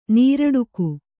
♪ nīraḍuku